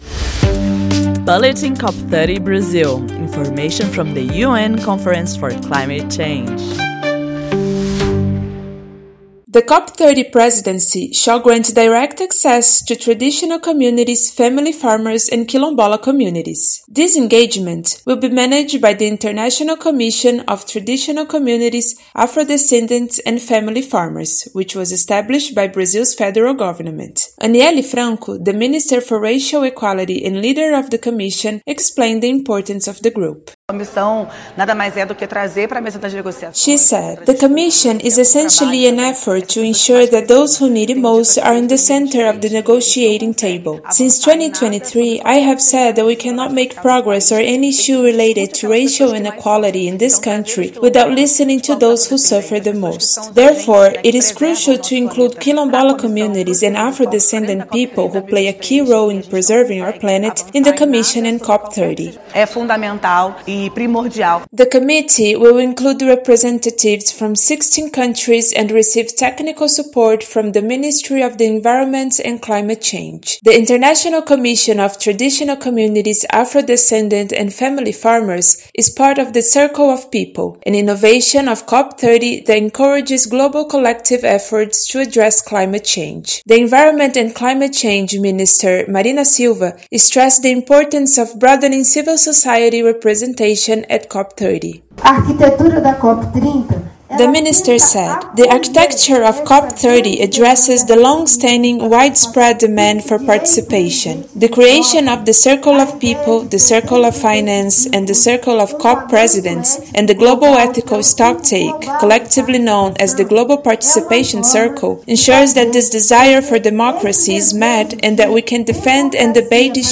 Led by the Minister for Racial Equality, Anielle Franco, the group represents the Circle of Peoples, an initiative of the COP30 presidency that provides a platform for civil society. Listen to the full report to learn more.